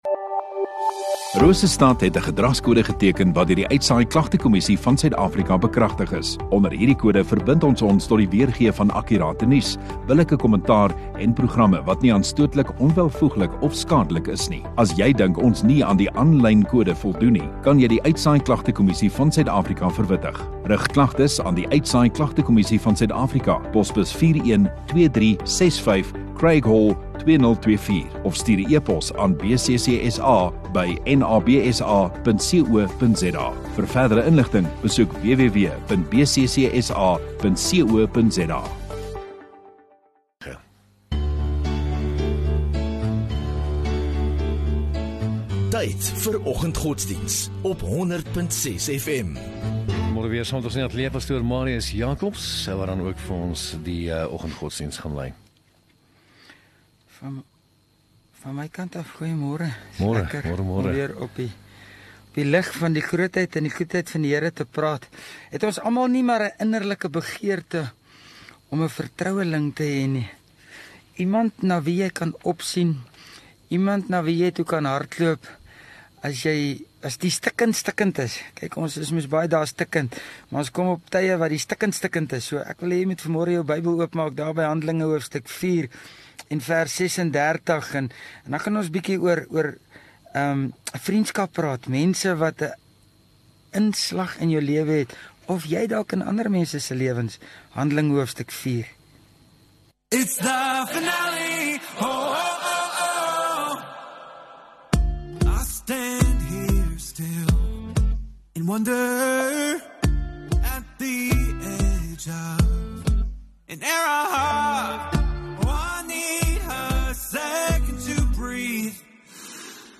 16 May Donderdag Oggenddiens